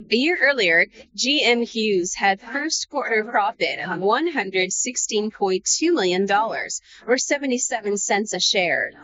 audio-denoising audio-to-audio speech-enhancement
Speech Enhancer